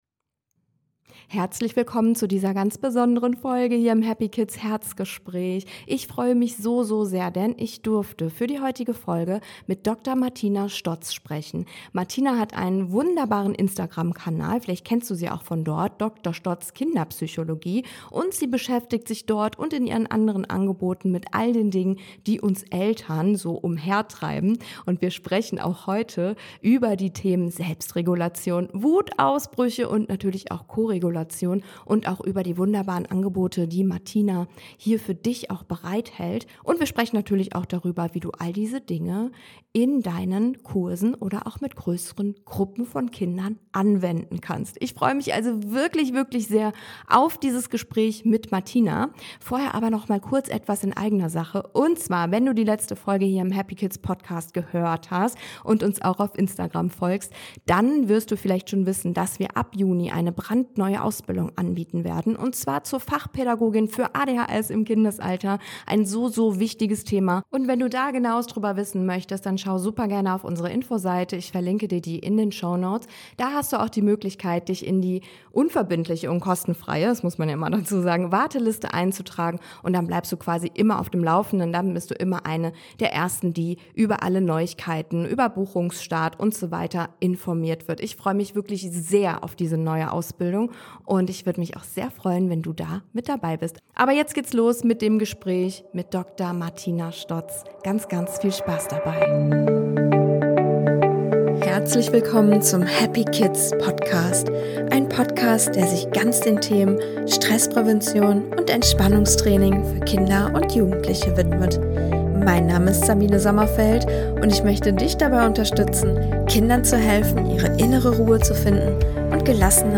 HAPPYKIDS HERZGESPRÄCH